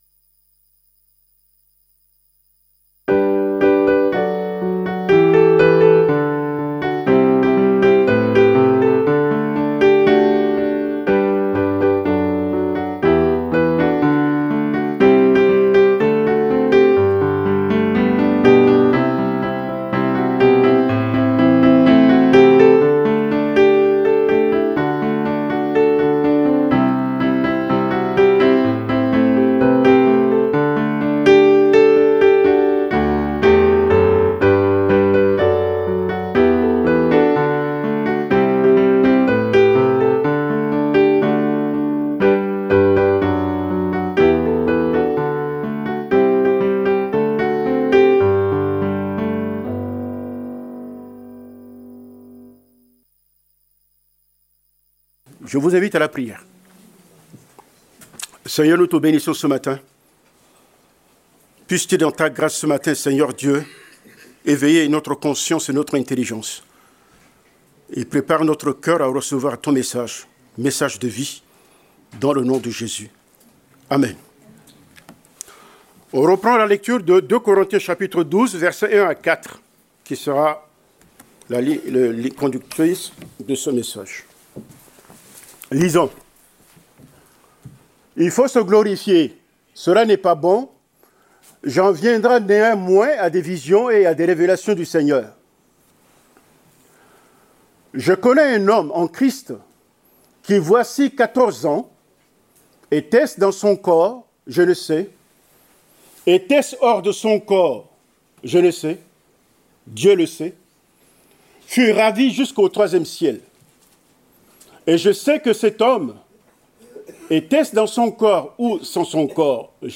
Prédication du 16 février 2025.